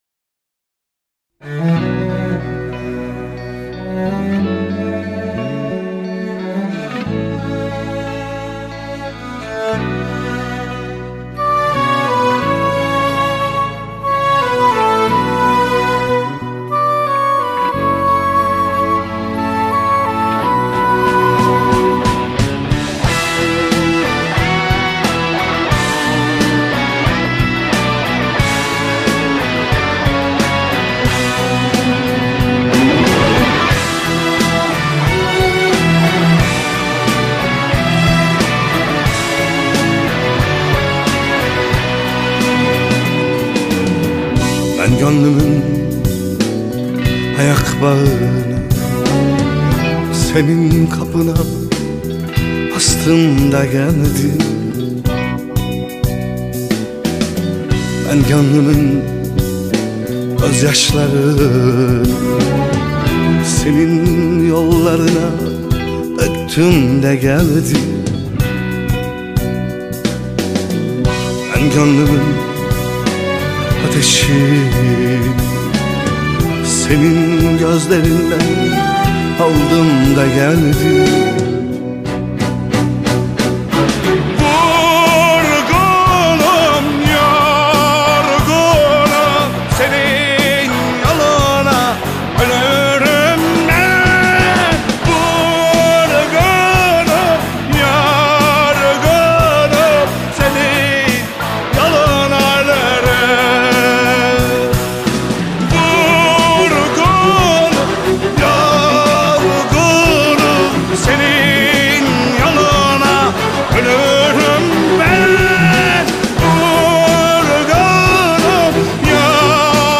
Anatolian Rock, Arabesque Rock, Turkish Rock